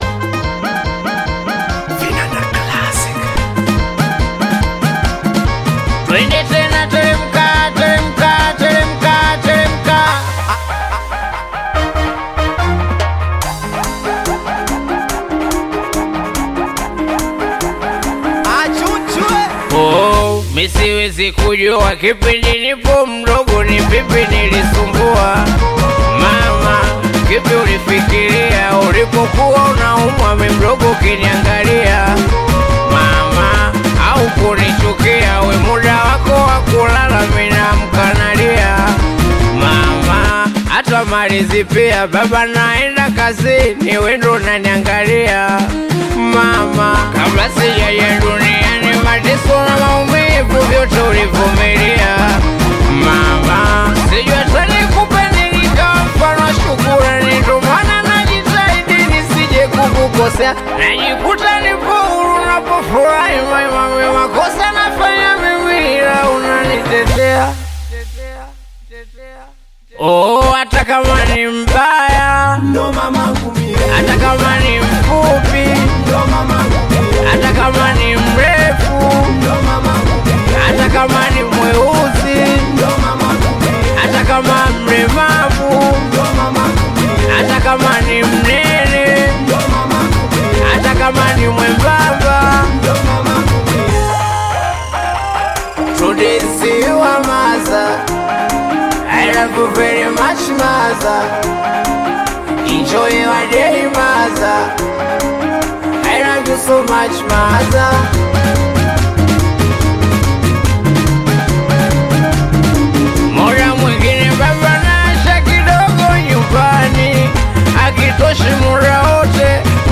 Tanzanian Bongo Flava Singeli
Singeli song